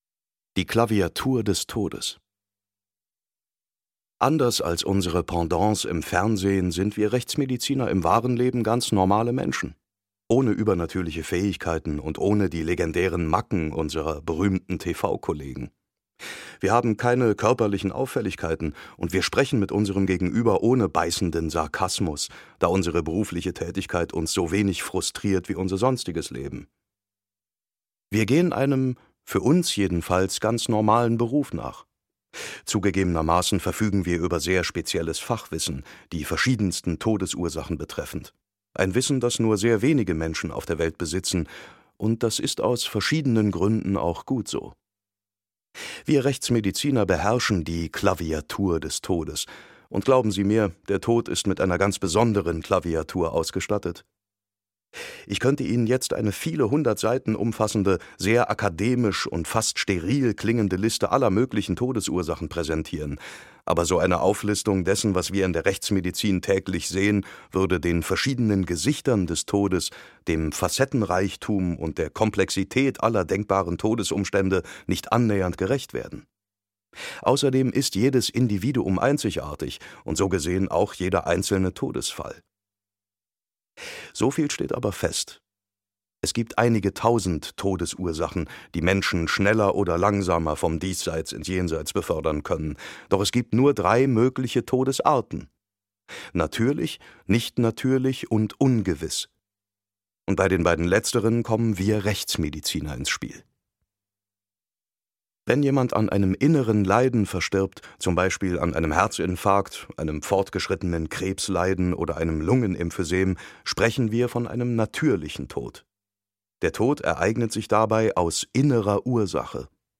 David Nathan (Sprecher)
Seine markante Stimme leiht er u. a. Johnny Depp, Christian Bale, Val Kilmer und Leonardo DiCaprio.
Reihe/Serie DAV Lesung